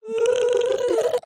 Minecraft Version Minecraft Version 1.21.5 Latest Release | Latest Snapshot 1.21.5 / assets / minecraft / sounds / mob / strider / retreat2.ogg Compare With Compare With Latest Release | Latest Snapshot